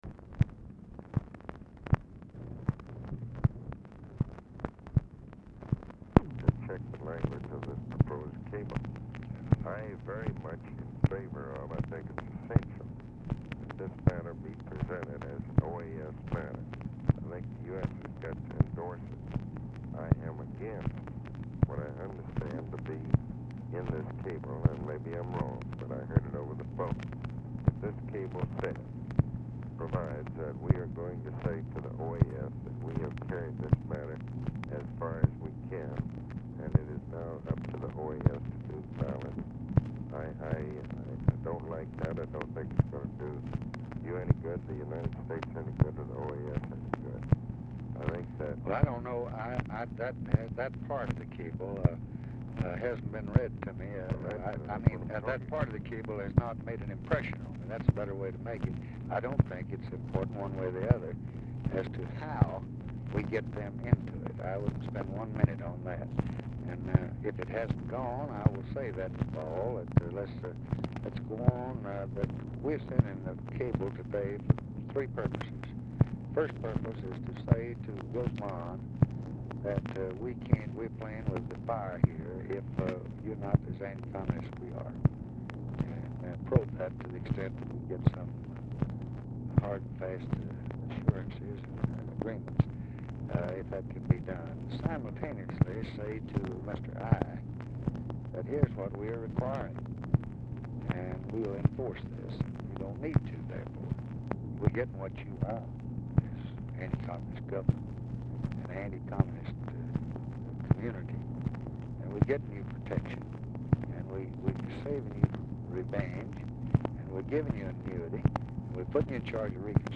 Telephone conversation # 7814, sound recording, LBJ and ABE FORTAS, 5/23/1965, 5:10PM | Discover LBJ
ROBERT MCNAMARA IS MEETING WITH LBJ, HAS BEEN LISTENING TO CONVERSATION AND SPEAKS TO FORTAS
Format Dictation belt
Location Of Speaker 1 Camp David, Catoctin Mountain Park, Maryland